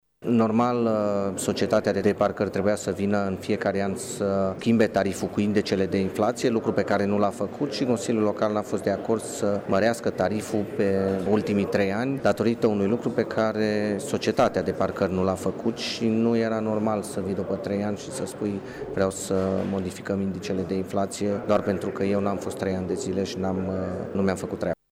Viceprimarul Claudiu Maior a explicat că societatea care administrează parcările trebuia să facă acest lucru din timp.